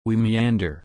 /miˈændə(ɹ)/